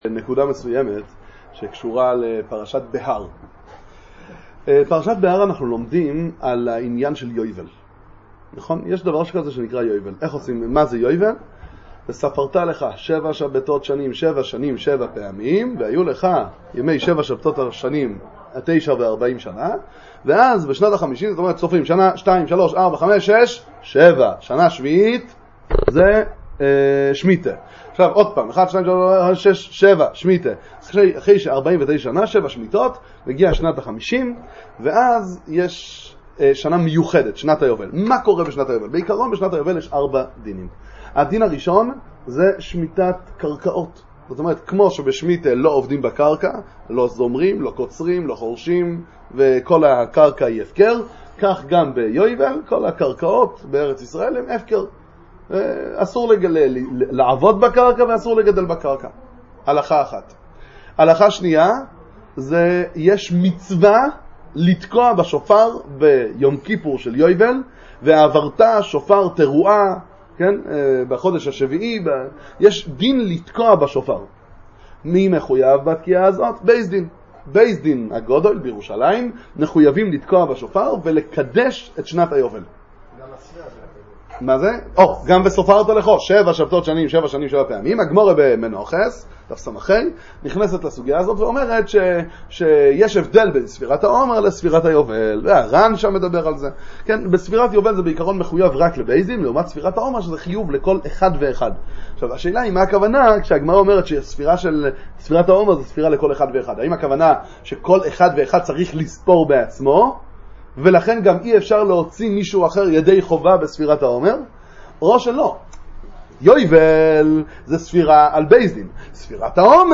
שיעור עיון בפרשת השבוע, ביאור סוגיית קדושת יובל, שיעורי תורה לצפיה על פרשת בהר